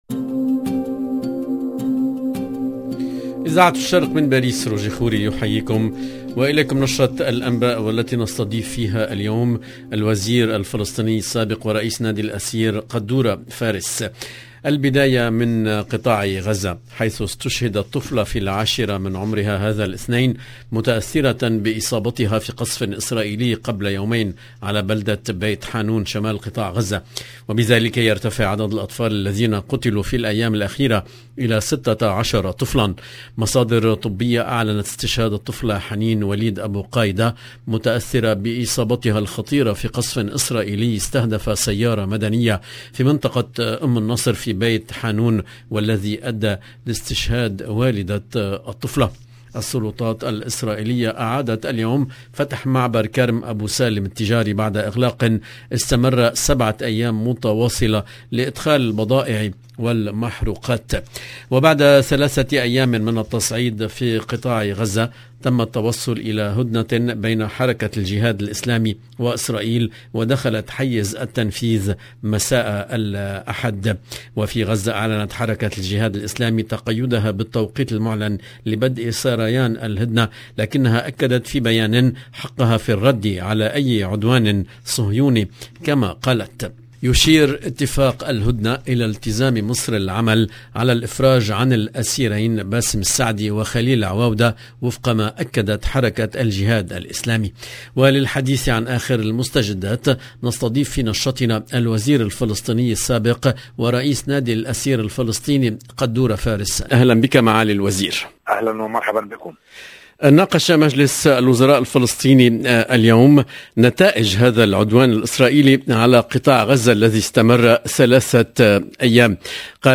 LE JOURNAL EN LANGUE ARABE DU SOIR DU 8/08/22